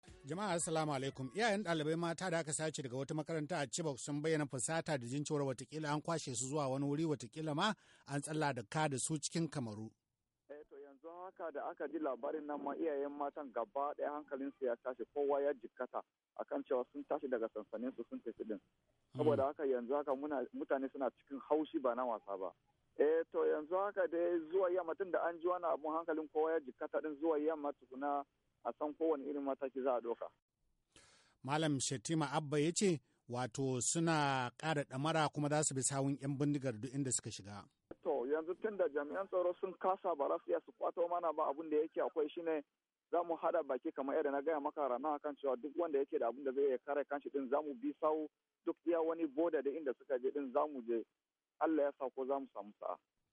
Iyayen dalibai mata da aka sace daga wata makaranta a Chibok sun bayyana fusata da jin cewa akwai yiwuwar an kwashe su zuwa wani wurin, watakila ma an tsallaka da su zuwa Kamaru da Chadi. Daya daga cikin mutanen Chibok da yayi hira da Dandalin VOA ta wayar tarho